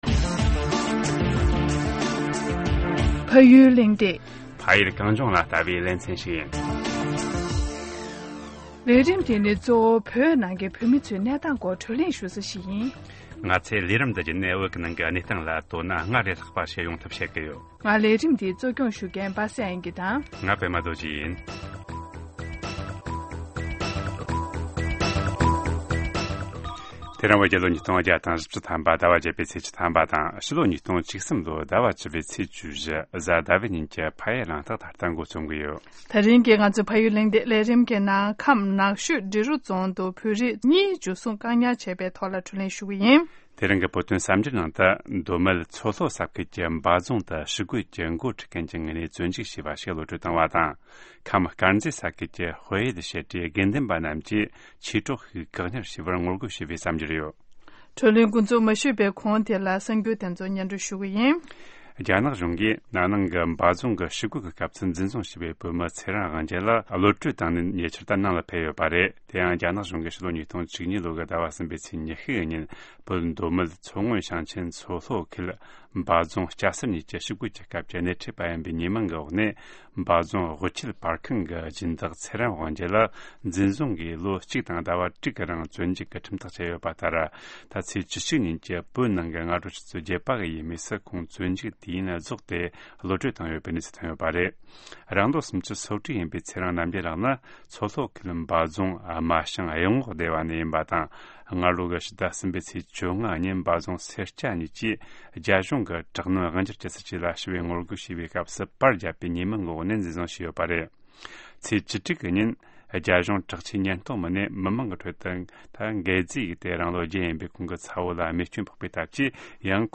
བགྲོ་གླེང་ཞུས་པ་ཞིག་ཡིན།